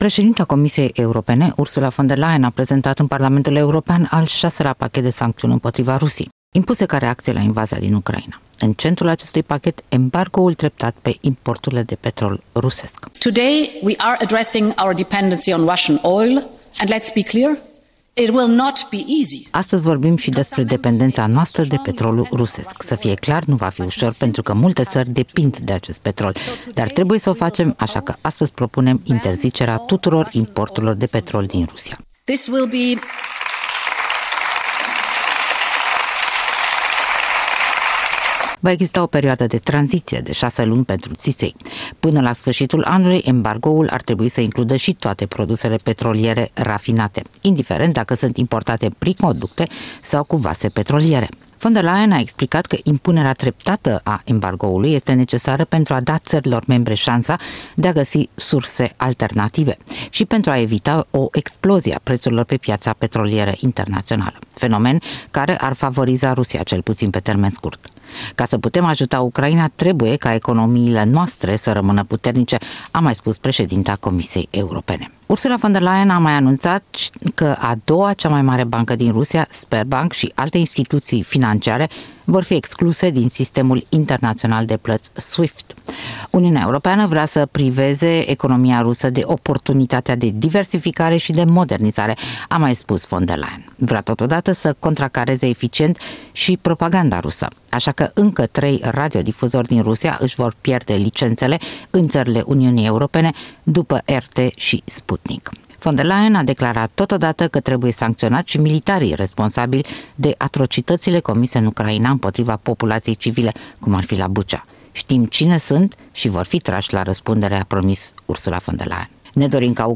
Președinta Comisiei Europene Ursula von der Leyen în timpul dezbaterii asupra consecințelor sociale și economice pentru UE ale războiului Rusiei în Ucraina, Parlamentul European, Strasbourg, 4 mai 2022.